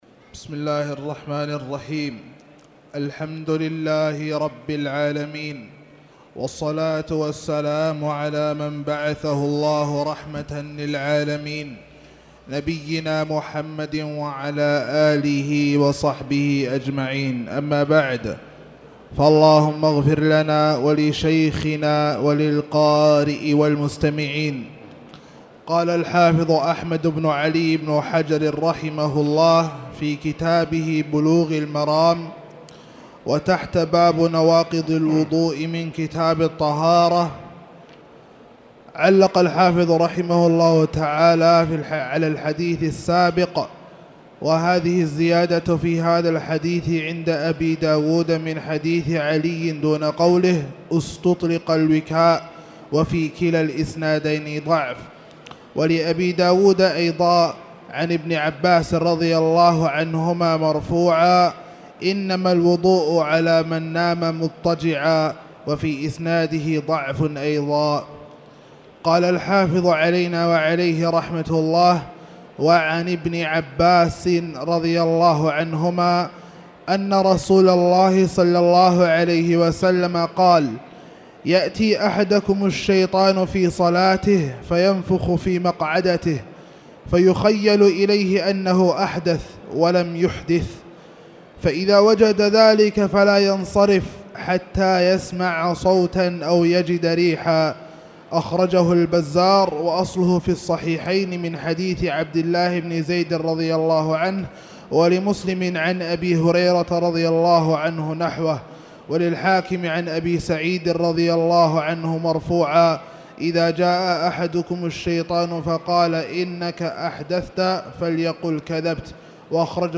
تاريخ النشر ٢٠ ربيع الثاني ١٤٣٨ هـ المكان: المسجد الحرام الشيخ: فضيلة الشيخ أ.د. خالد بن عبدالله المصلح فضيلة الشيخ أ.د. خالد بن عبدالله المصلح كتاب الطهارة-نواقض الوضوء (2)